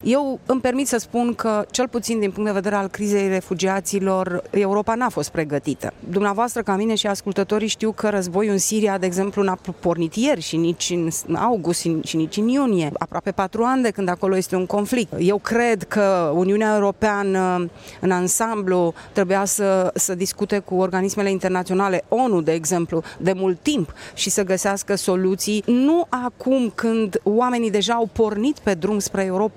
Europa are o problemă în gestionarea crizelor, afirmă eurodeputatul Maria Grapini.